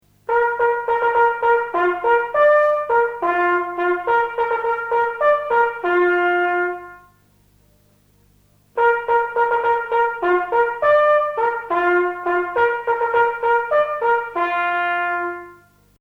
Die Posthorn-Signale werden immer zweimal gespielt.
Posthorn-Signal: Abgang einer jeden Post (MP3)